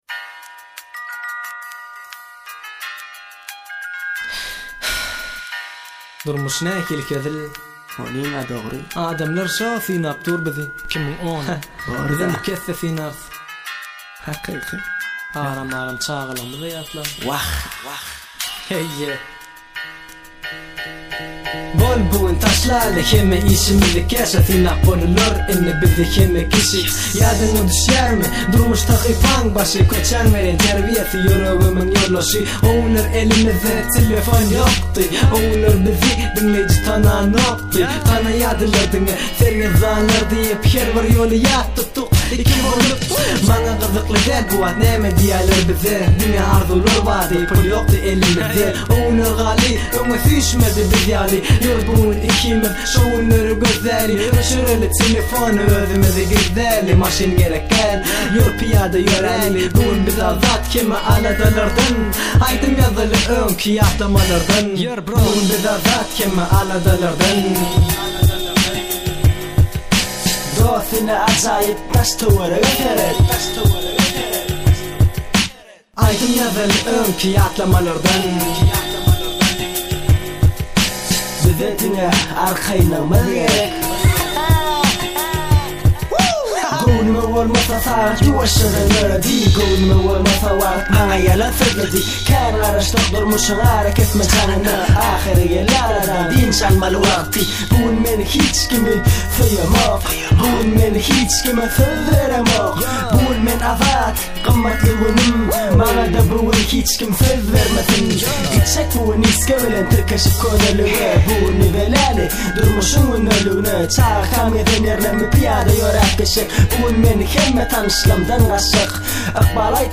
turkmen rap